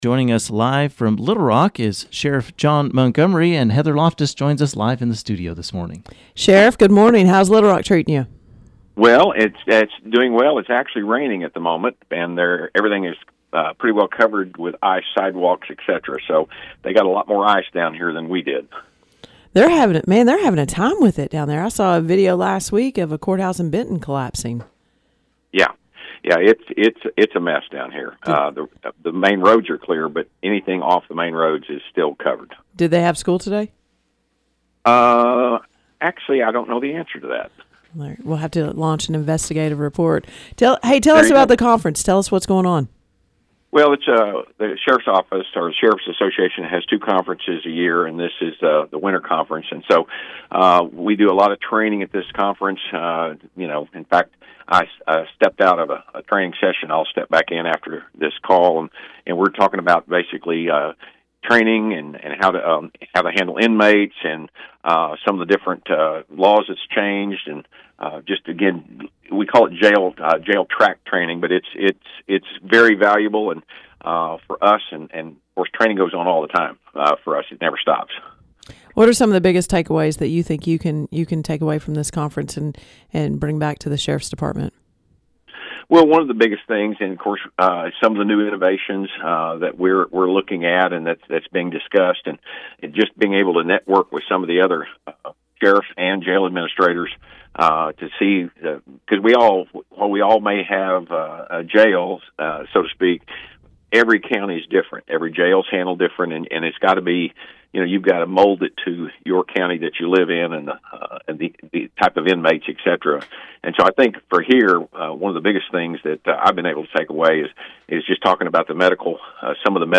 Sheriff John Montgomery calls in live from Little Rock
Baxter County Sheriff John Montgomery spoke with KTLO News live from Little Rock, to discuss training and give thanks to deputies for their hard work through the winter weather.